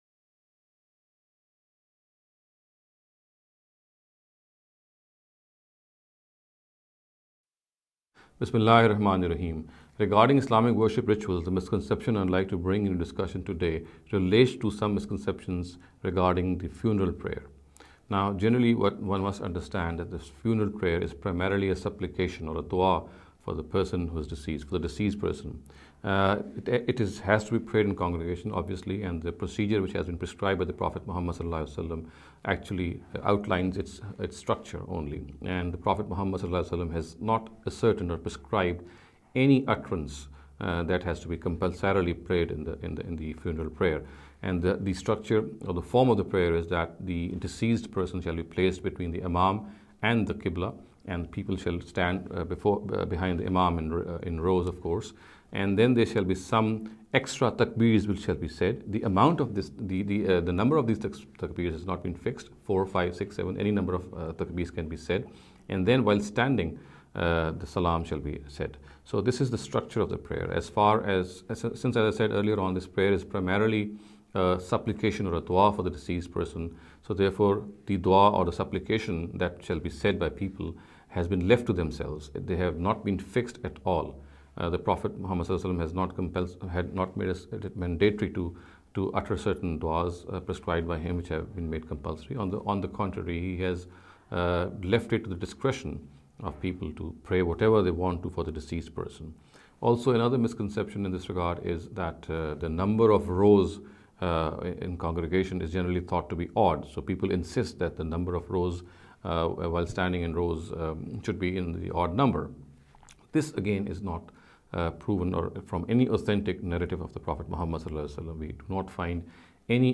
This lecture series will deal with some misconception regarding the Islamic Worship Ritual.